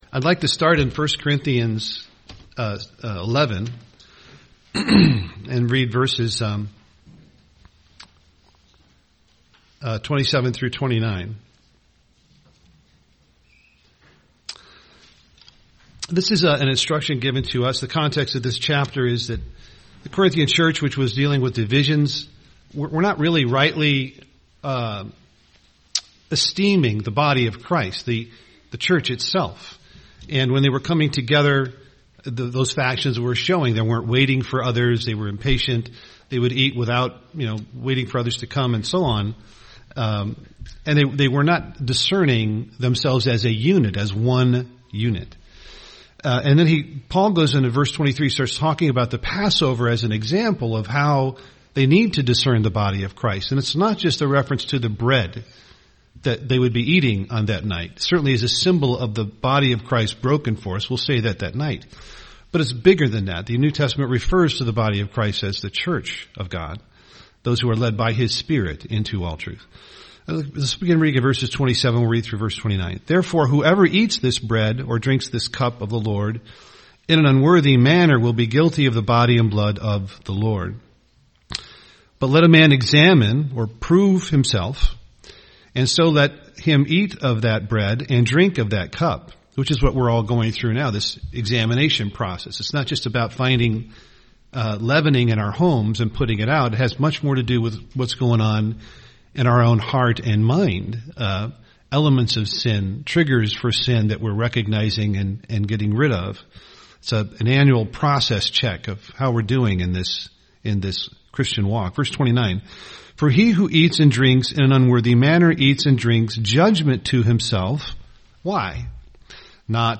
UCG Sermon Christ The mind of Christ Passover Studying the bible?